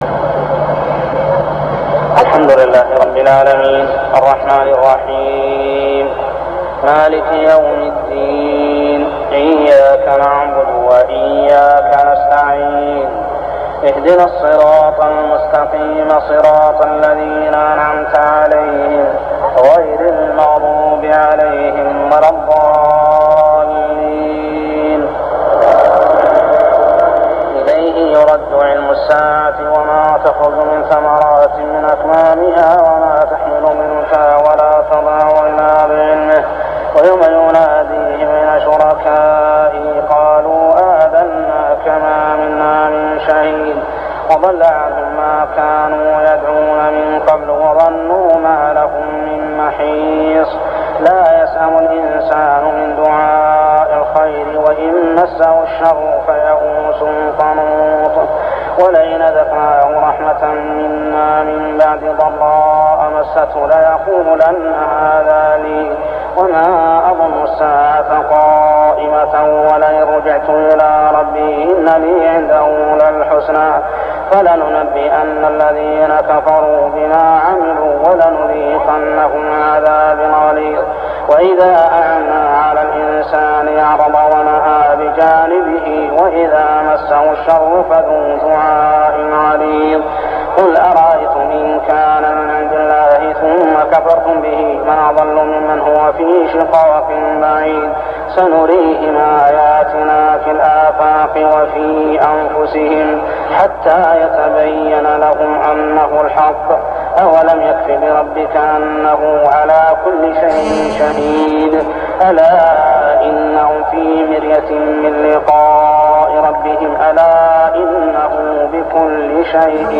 صلاة التراويح عام 1399هـ سور فصلت 47-54 و الشورى كاملة ( التسجيل من الآية 46 حتى الآية 53 مفقود ) و الزخرف 1-35 | Tarawih prayer Surah Fussilat, Ash-Shura, and Az-Zukhruf > تراويح الحرم المكي عام 1399 🕋 > التراويح - تلاوات الحرمين